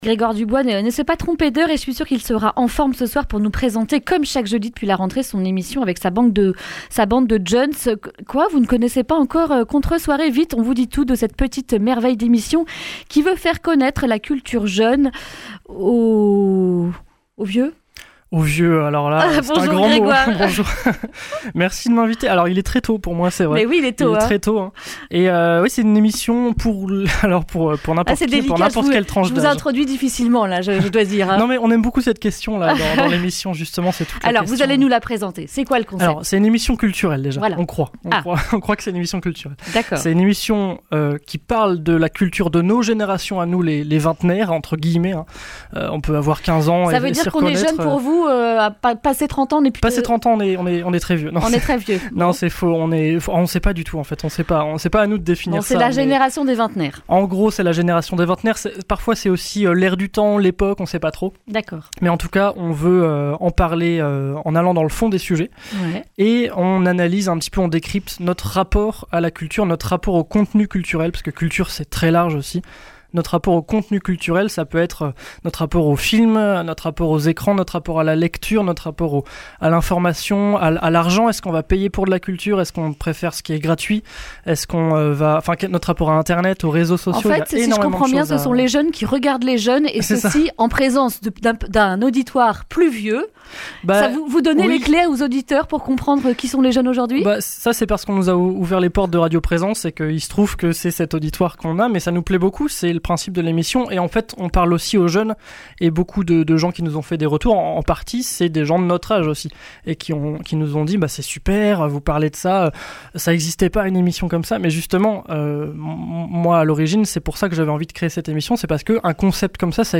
jeudi 12 décembre 2019 Le grand entretien Durée 11 min
Une émission présentée par